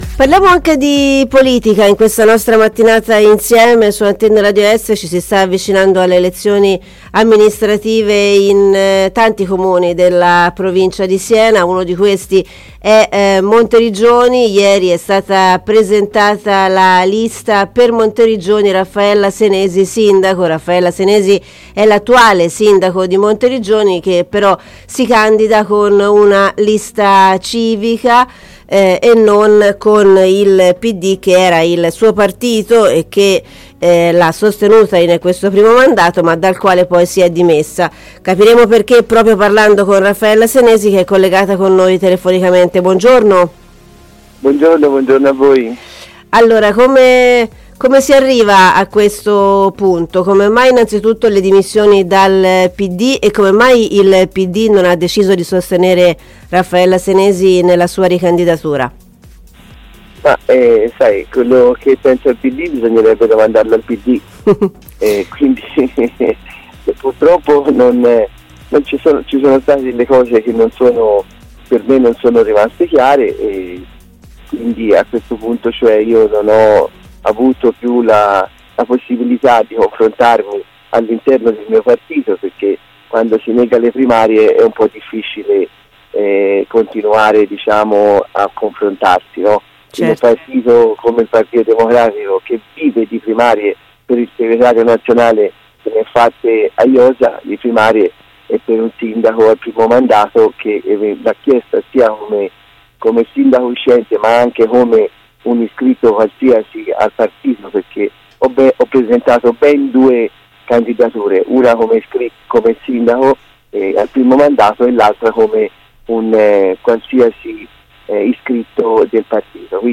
Stamani è stata ospite ai microfoni di ARE …